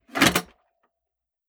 Ammo Crate Open 001.wav